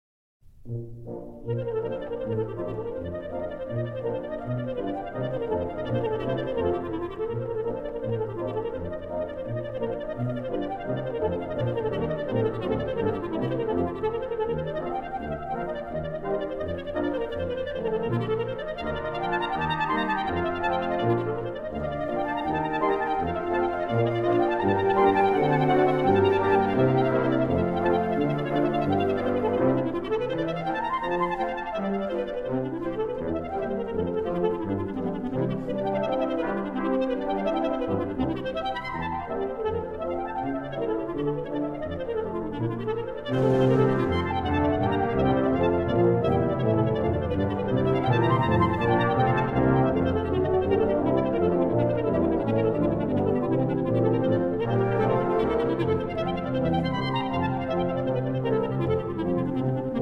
virtuoso music for clarinet and wind band